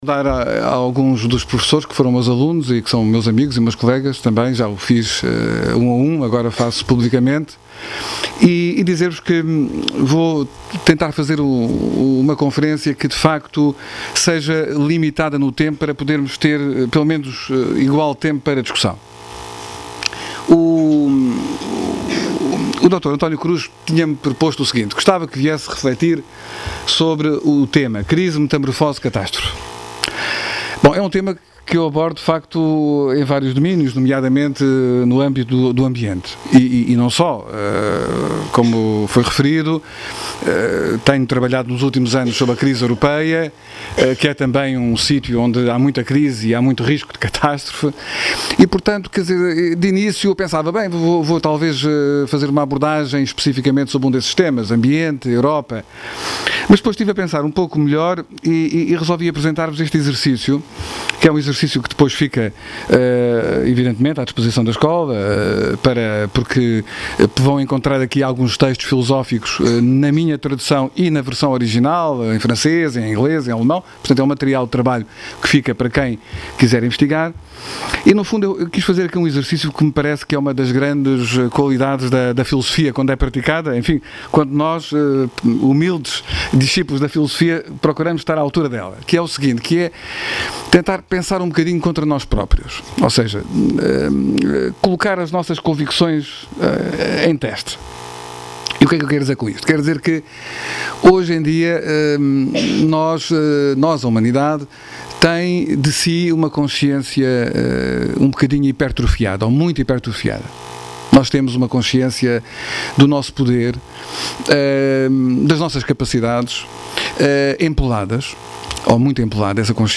Conferência